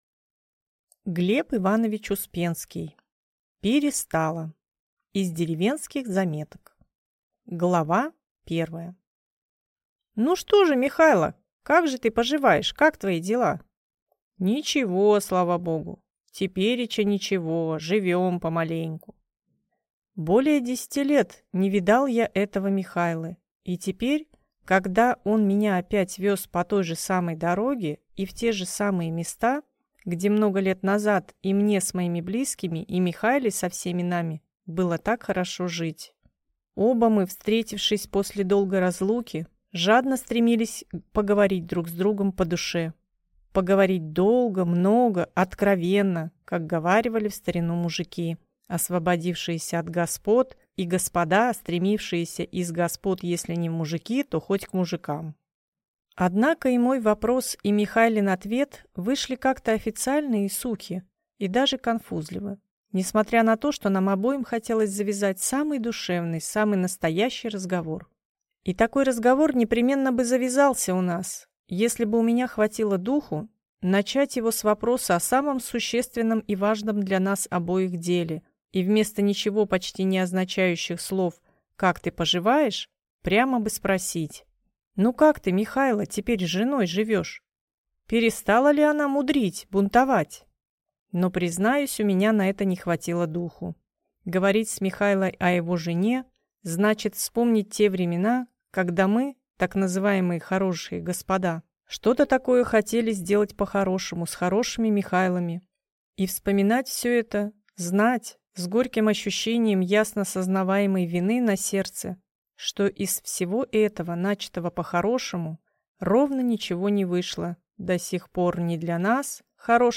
Аудиокнига «Перестала!» (Из деревенских заметок) | Библиотека аудиокниг